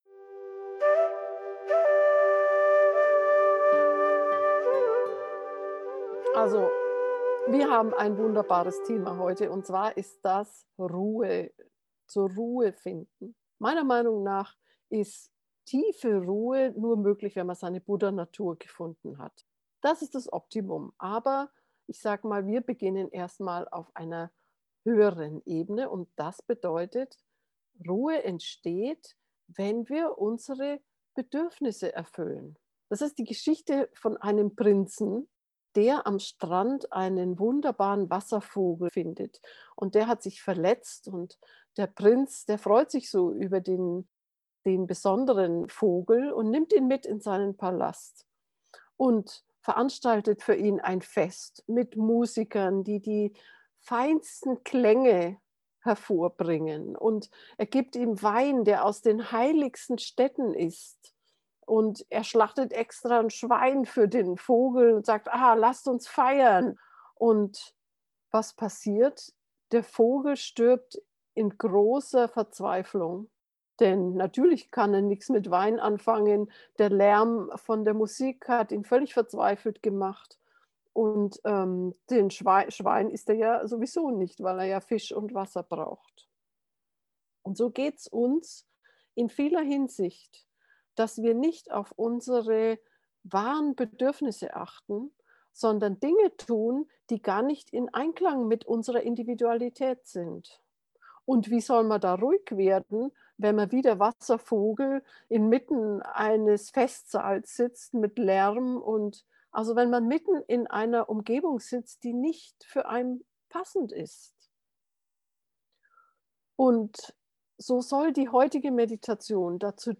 ruhe-beduerfnisse-gefuehrte-meditation